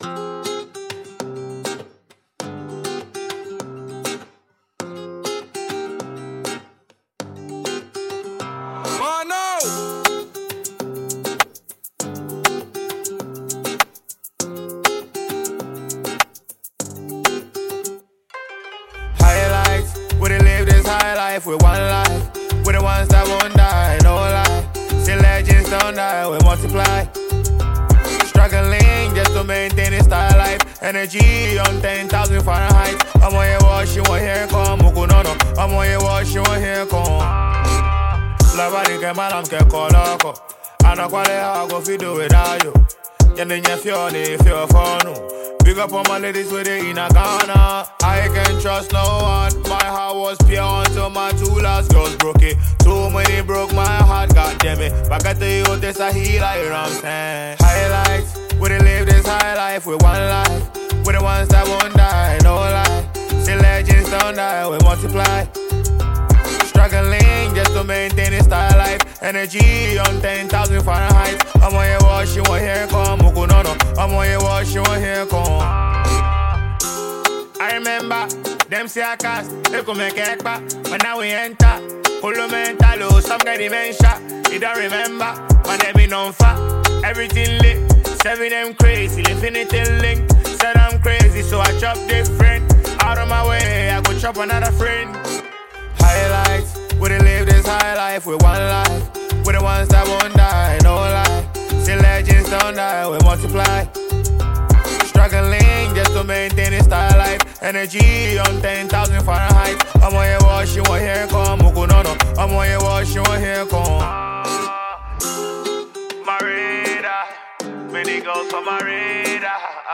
Ghanaian Afropop/Afrobeat singer and songwriter